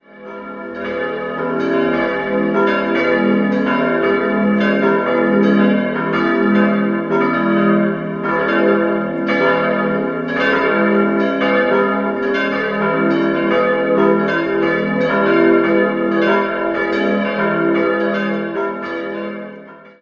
4-stimmiges Salve-Regina-Geläute: f'-a'-c''-d'' Die Eisenhartgussglocken wurden 1921 von der Gießerei Ulrich&Weule in Apolda-Bockenem gegossen.